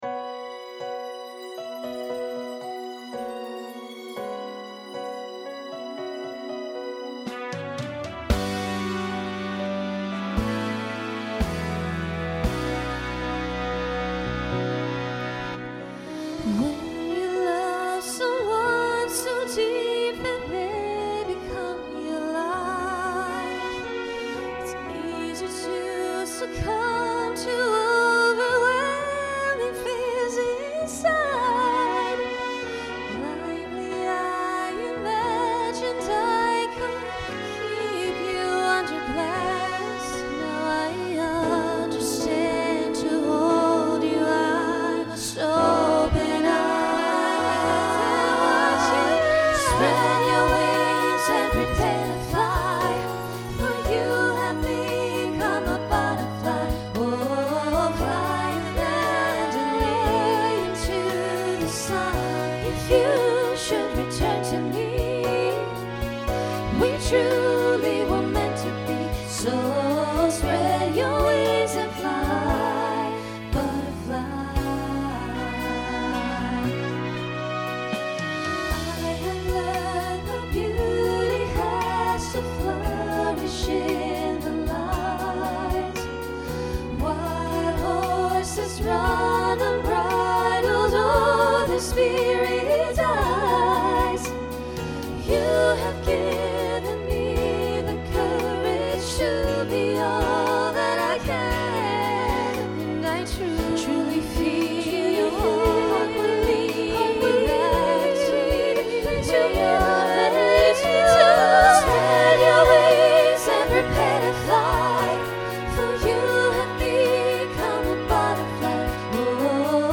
Voicing SSA Instrumental combo Genre Pop/Dance
Function Ballad